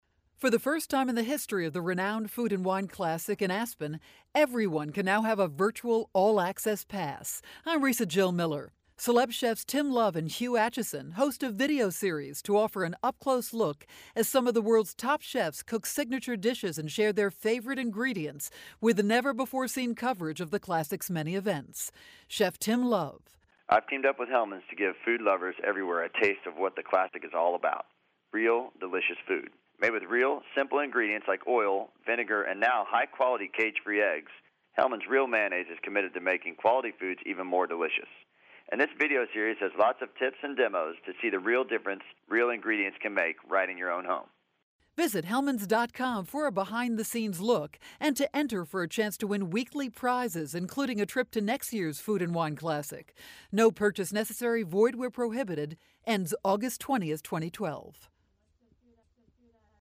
July 11, 2012Posted in: Audio News Release